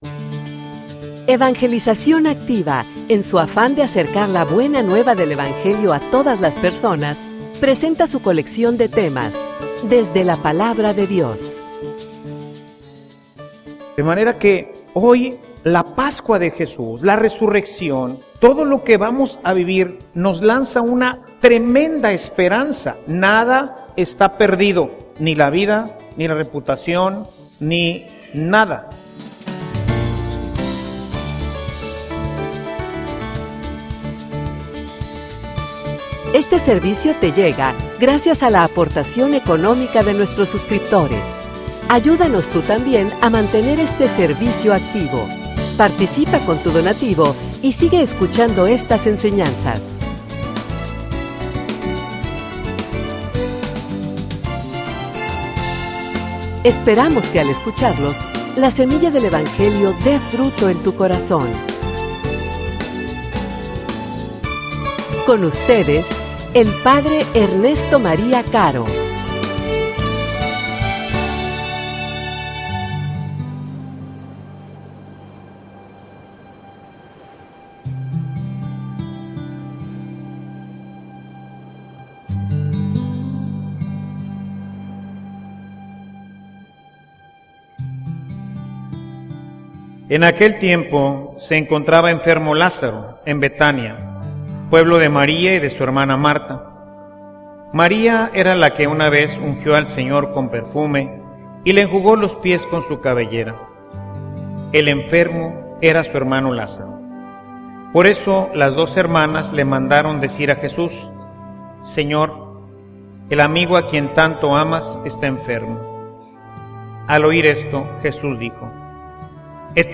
homilia_Nuestros_sepulcros.mp3